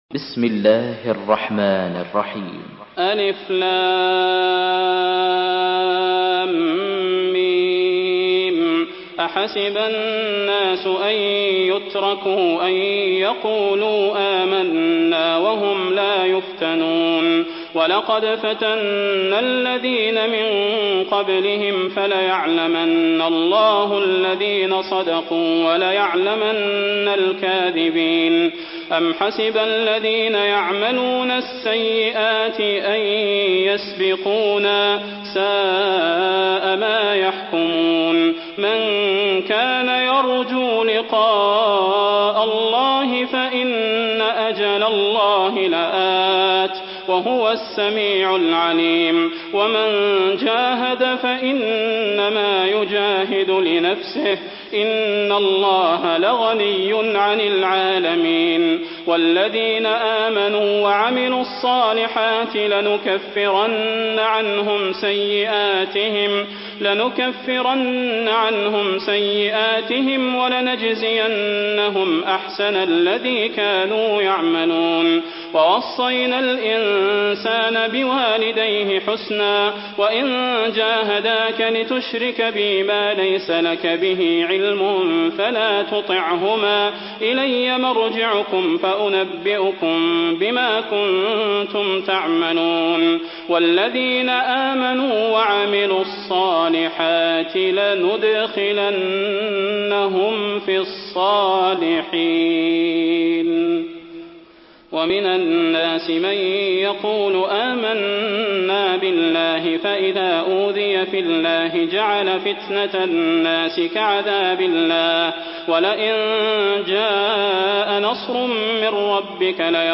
Surah আল-‘আনকাবূত MP3 by Salah Al Budair in Hafs An Asim narration.
Murattal Hafs An Asim